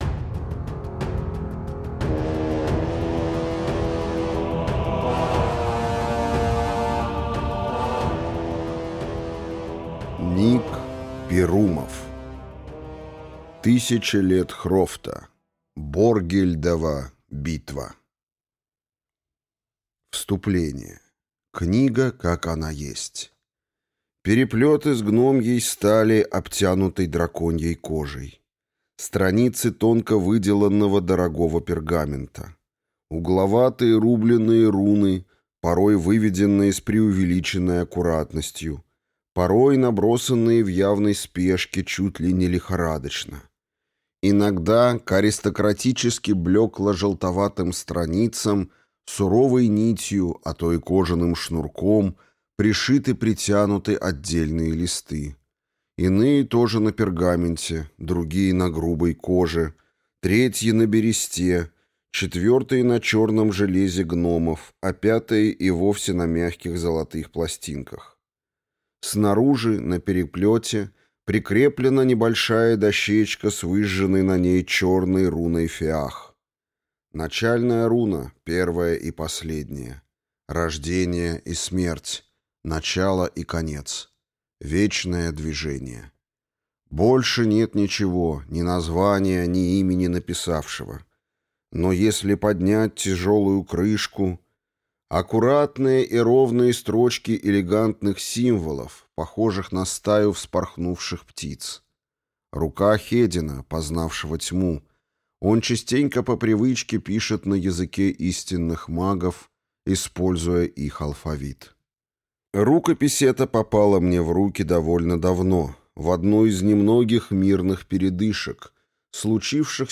Аудиокнига Тысяча лет Хрофта. Боргильдова битва | Библиотека аудиокниг